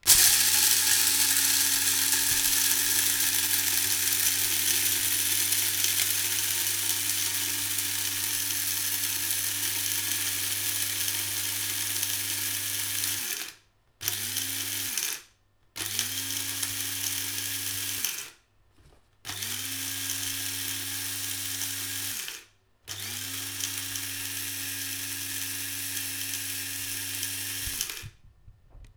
• small cofee grinder.wav
Small coffee grinder, gridning some fresh coffee beans in my kitchen, recorded with a Tascam DR 40.
small_cofee_grinder_naM.wav